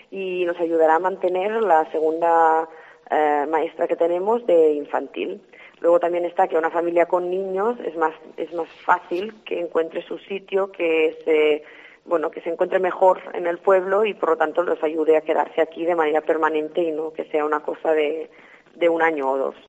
La alcaldesa de Peramola Gemma Orrit explica las razones del porqué se busca uuna familia con hijos